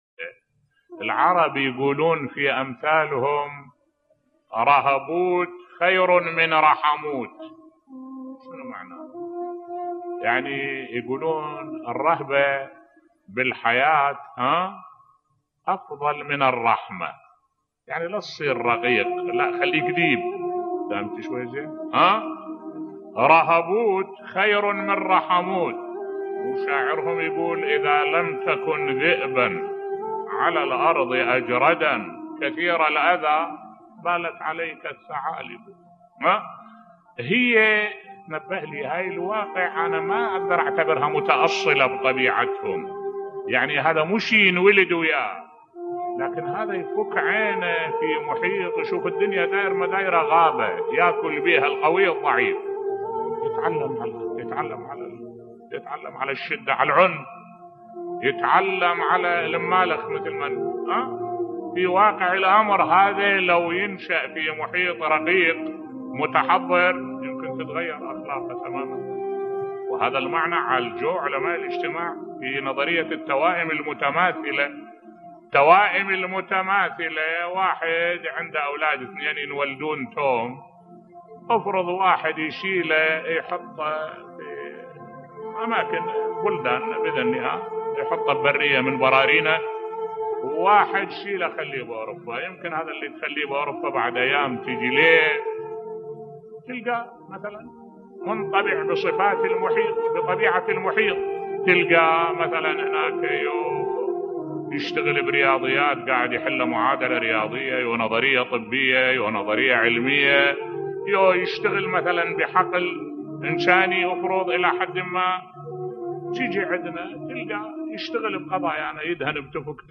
ملف صوتی تأثير المحيط في تشكيل شخصية الانسان بصوت الشيخ الدكتور أحمد الوائلي